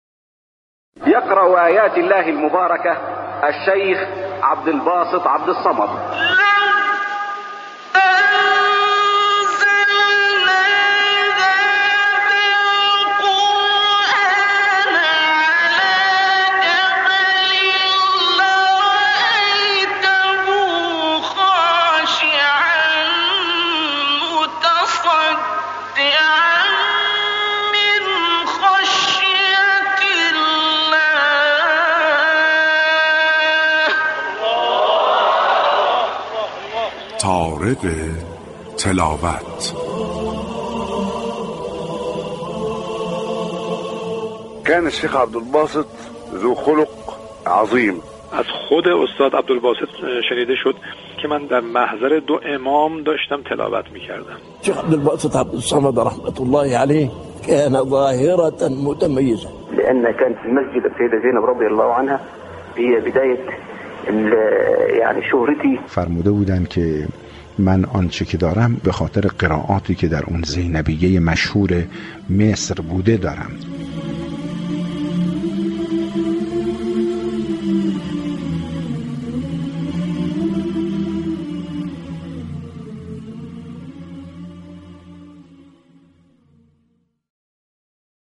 ویژه برنامه طارق تلاوت به مناسبت سالروز رحلت استاد «عبدالباسط محمد عبدالصمد» نهم آذرماه جاری به صورت زنده از رادیو قرآن پخش می شود.